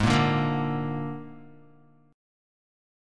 Absus2b5 chord